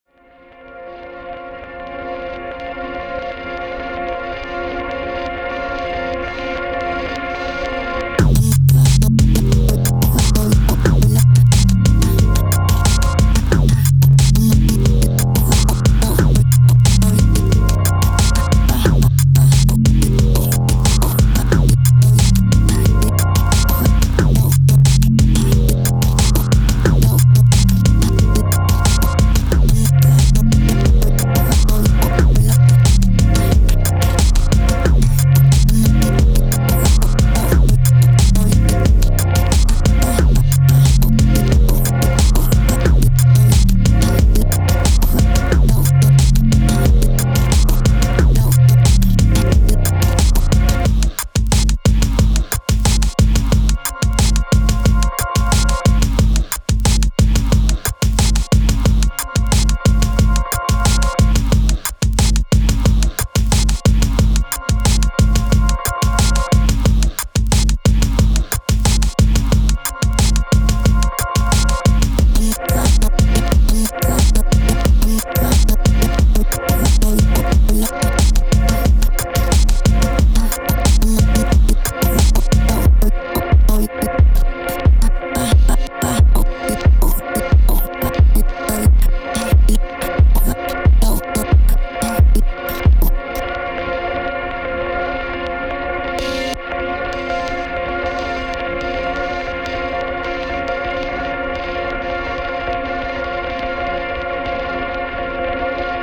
Roland SP-404 MK1 factory samples